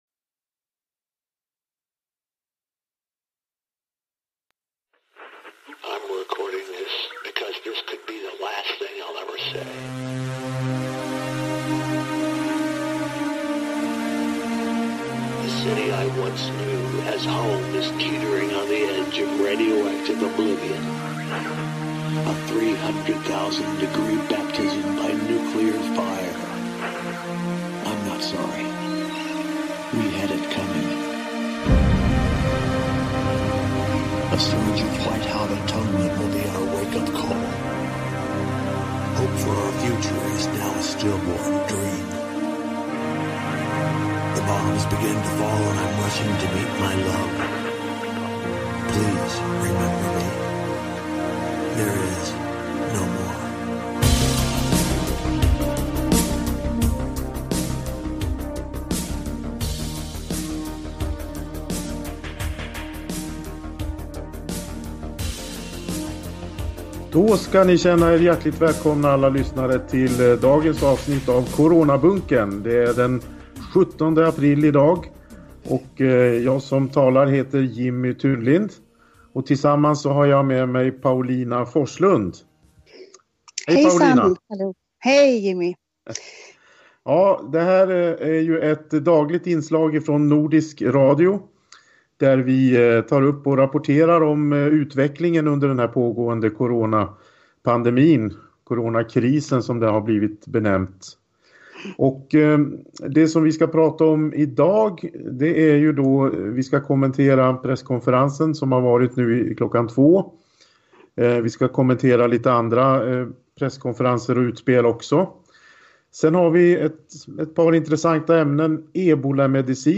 NYHETER. Coronabunkern ger dig senaste nytt om den pågående samhällskrisen.